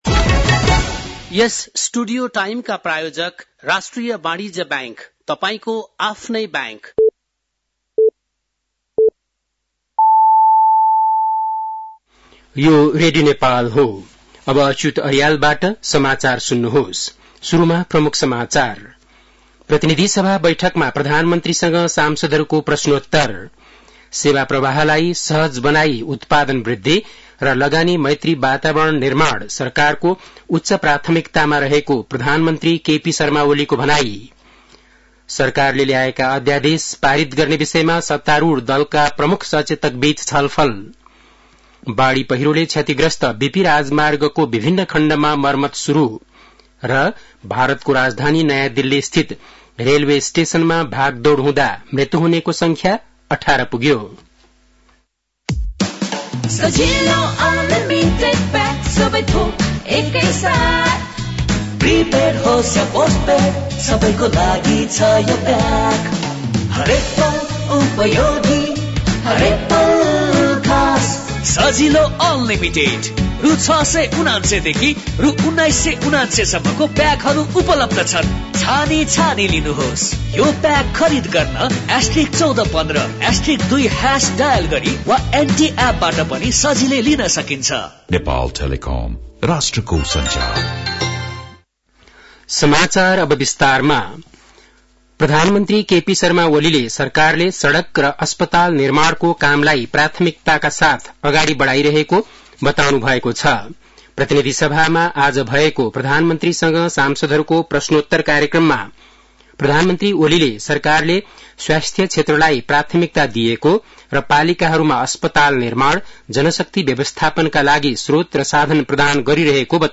बेलुकी ७ बजेको नेपाली समाचार : ५ फागुन , २०८१
7-pm-nepali-news-11-04.mp3